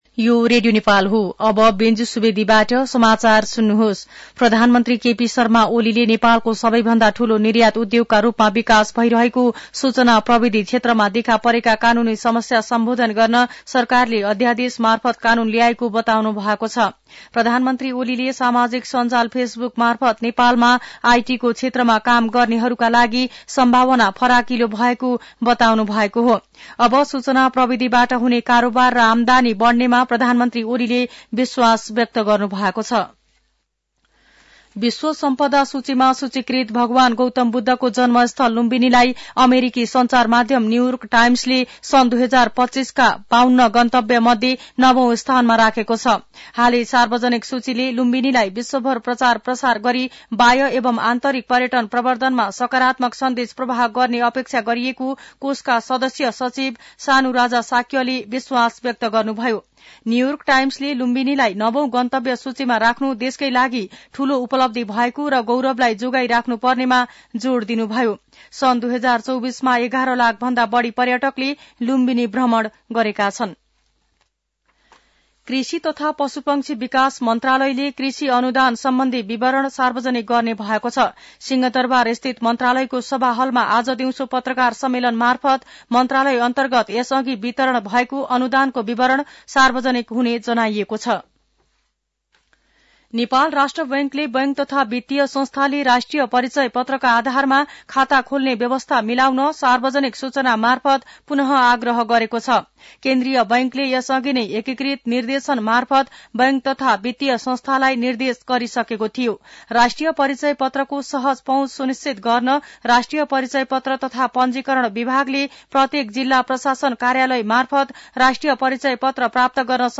मध्यान्ह १२ बजेको नेपाली समाचार : २९ पुष , २०८१
12-am-news.mp3